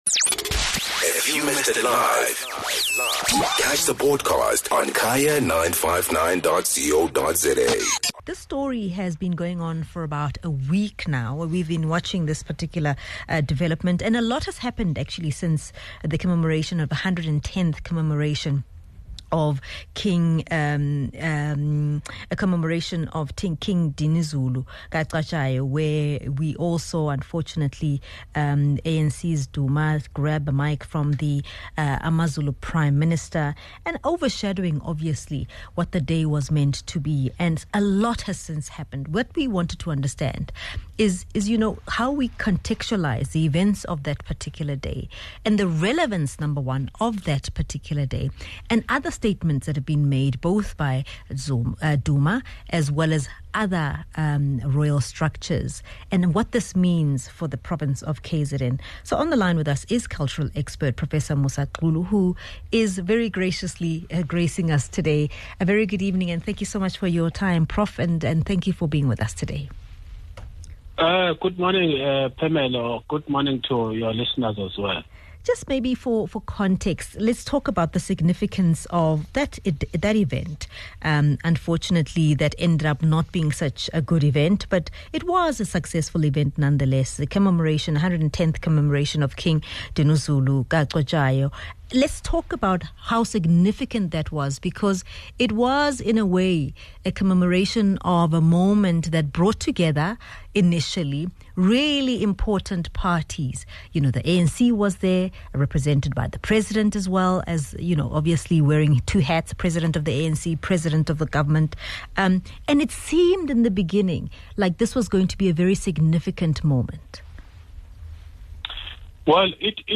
21 Mar Analysis: ANC KZN & traditional Prime Minister